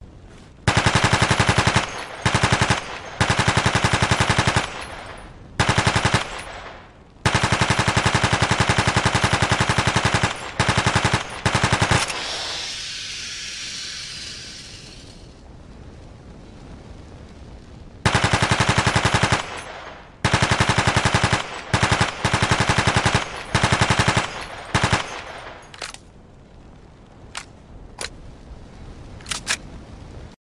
Tiếng súng RPK
Download tếng súng RPK mp3, tải hiệu ứng âm thanh tiếng súng RPK bắn mp3, to nhất hay nhất dùng để cài nhạc chuông, mở troll, triêu đùa bạn bè, dựng phim...